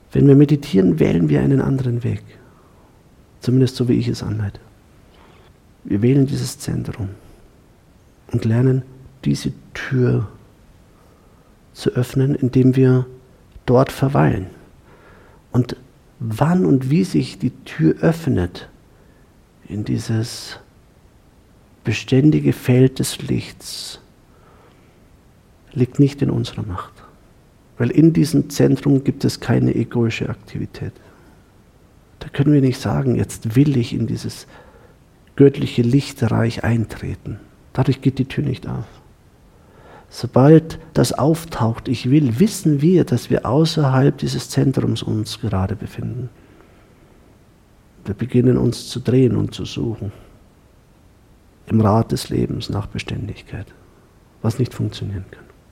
Meditation Live-Aufnahme